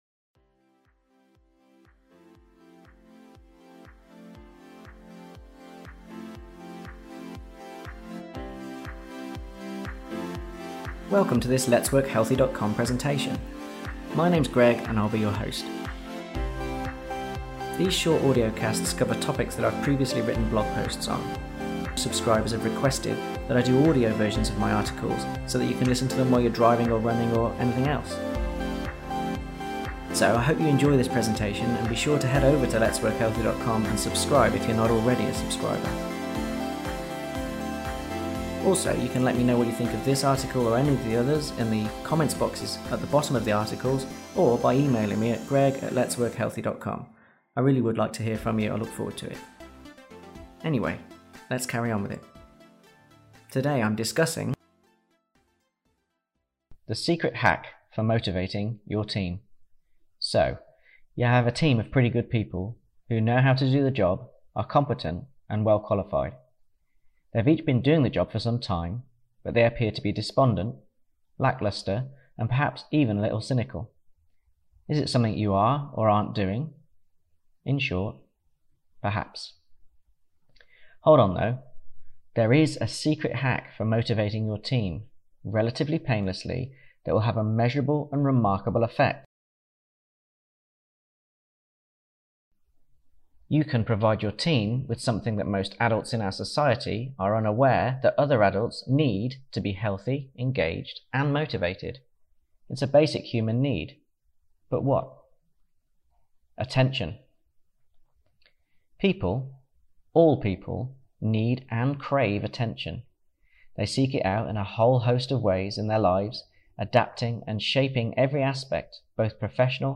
If you would like to listen to the audio version of this article – click the play button below: